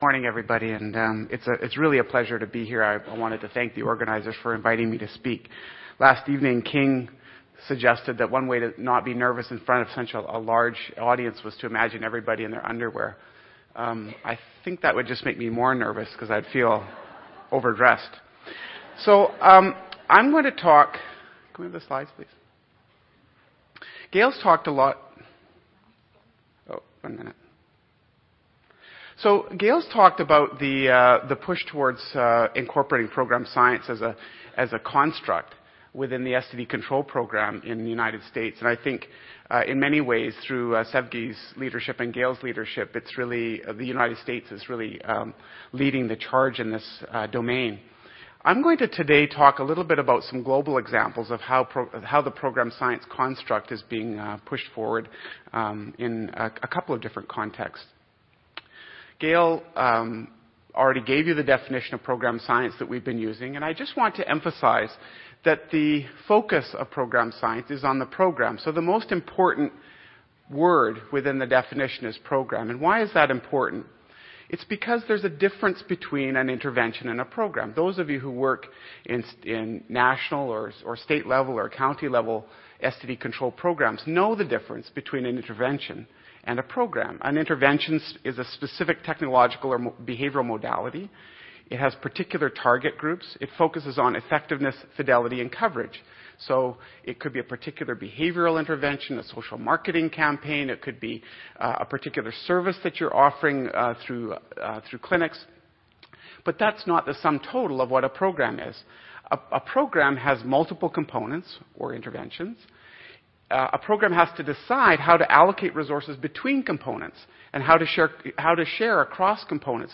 See more of: Plenary Session 1: Program Science See more of: Oral and Poster << Previous Abstract | Next Abstract